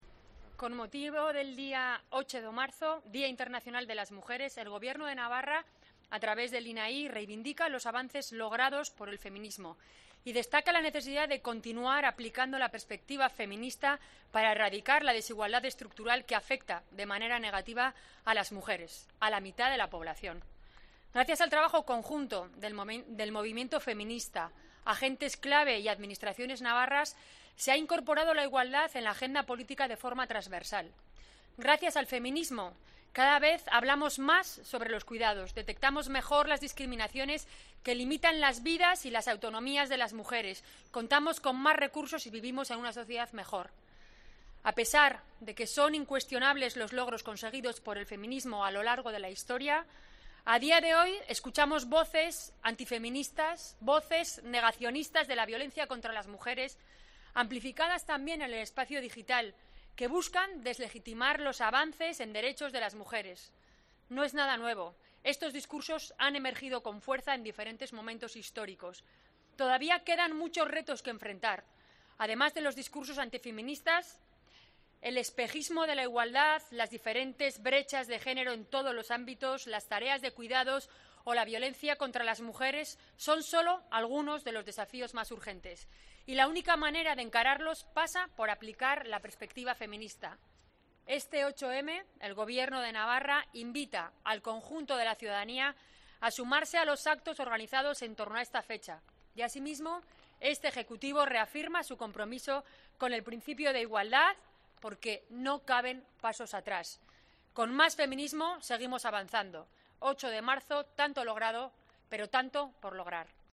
Declaración Institucional Gobierno Navarra